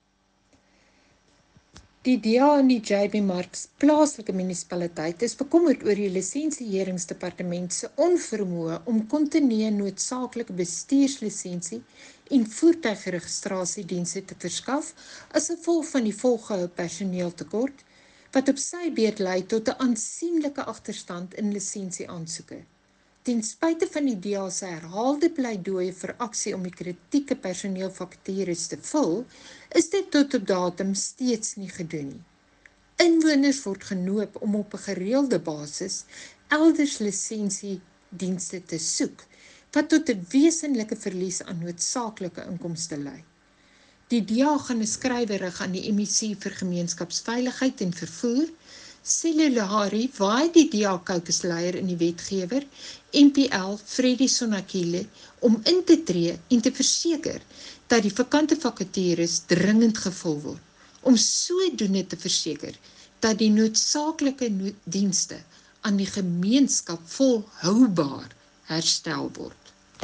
Note to Broadcasters: Please find linked soundbites in
Rdl-Jeanne-Adriaanse-Lisensie-Dept-Afr.mp3